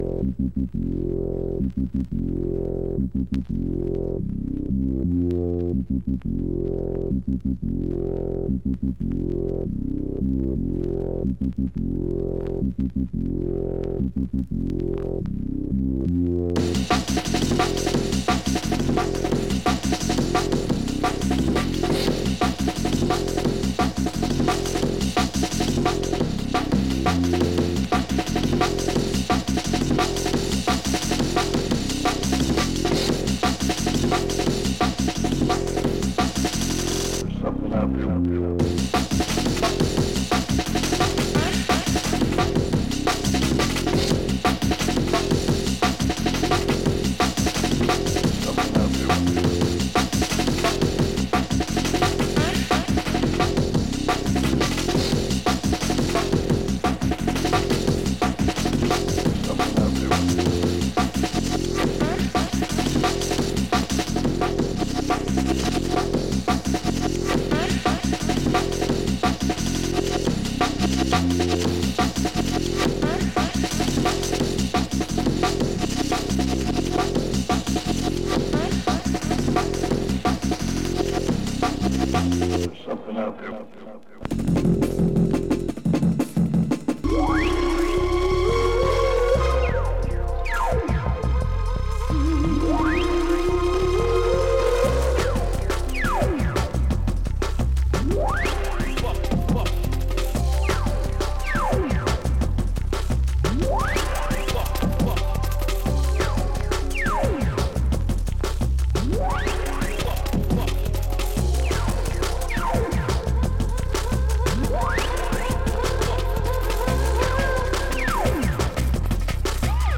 Light surface marks, vinyl plays to a VG+ grading